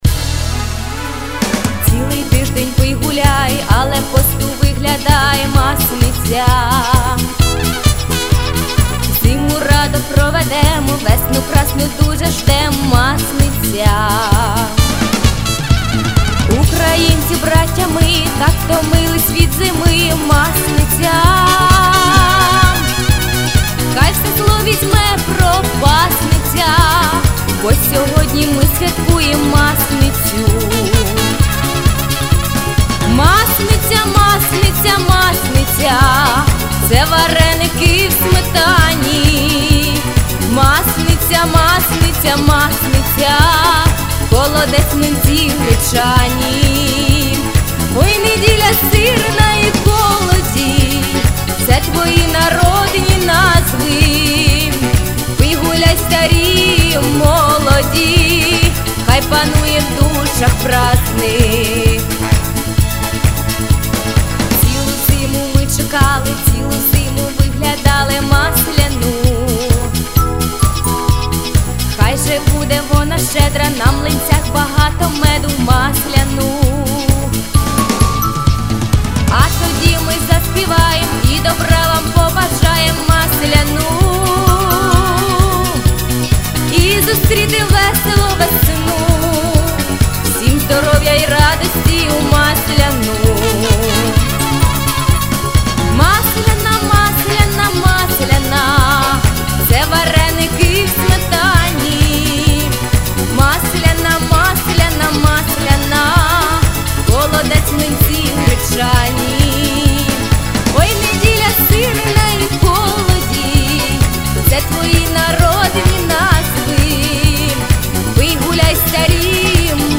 Рубрика: Поезія, Авторська пісня
Живо, бадьоро, одним словом - чудово! give_rose
По-друге - так, схоже на живий оркестр, але ні.
Це грає чудова, остання самограйка Roland BK-3, недорога і любительська (читай:я її люблю).Не на правах реклами, звичайно, але більш реалістичні стилі і звуки хіба-що у Кетрона чи Версі (фантастично дорогих) з якими не мав можливості працювати.